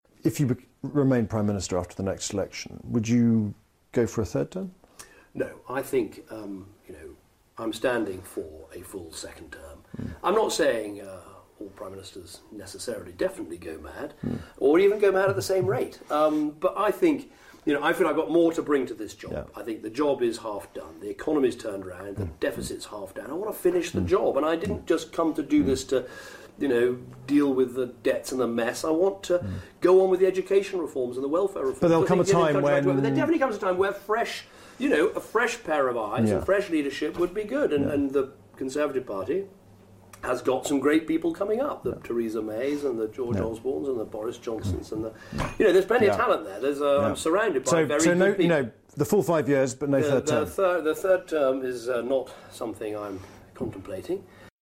Interview with the BBC's James Landale